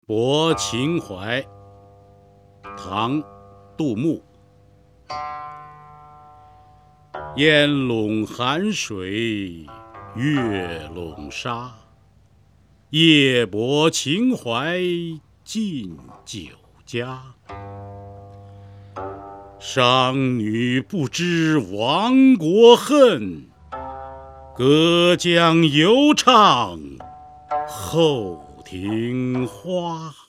杜牧《泊秦淮》原文和译文（含鉴赏、朗读）　/ 杜牧